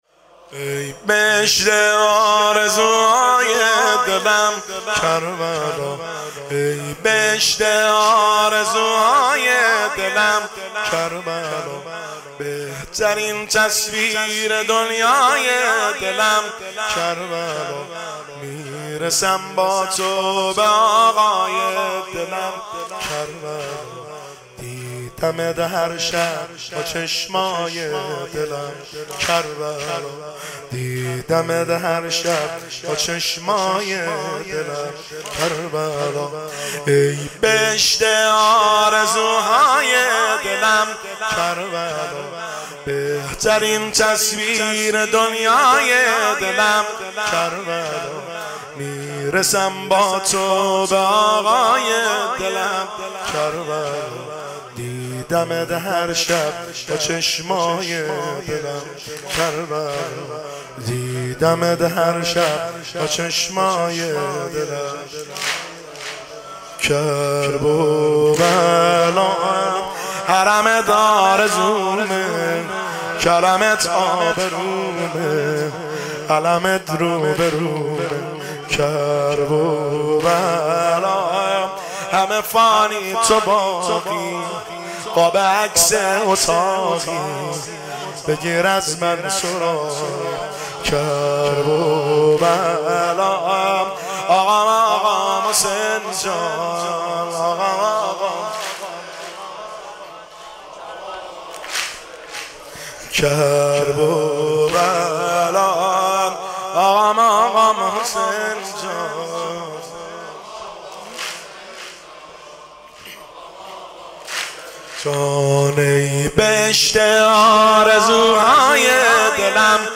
دانلود با کیفیت LIVE